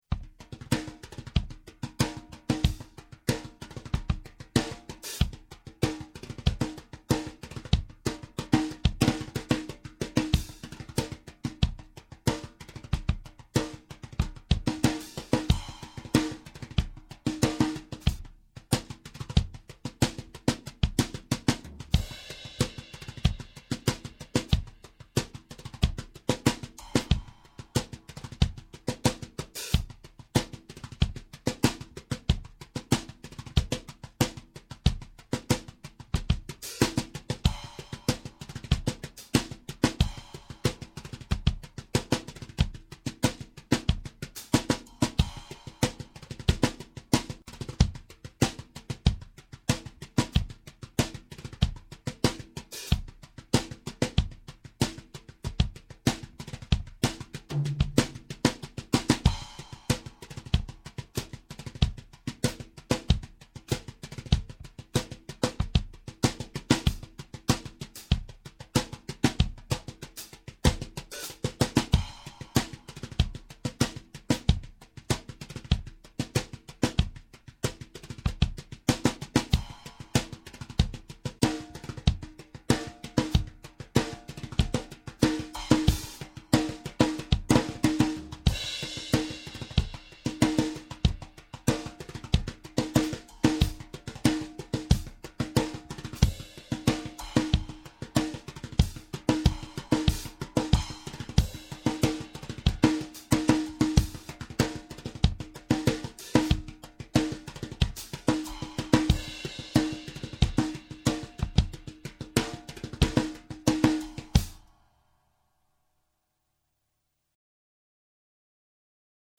Cocktail Drum,
Different Snare Sounds
Here are 2 mp3's with 4 different snare sound caused by remote snare off, on, with Moon Gel also off or on. The "brushes" mp3 are actually a type of Hotrod sticks and of course the "Sticks" are normal 5A sticks.
These tracks were recorded without any compression, gating or effects.
0:00-0:18 Moon Gel On, Remote Snare Off
0:18-0:47 Moon Gel On, Remote Snare On
0:47-1:21 Moon Gel Off, Remote Snare On
1:21-1:57 Moon Gel Off, Remote Snare Off
Brushes.mp3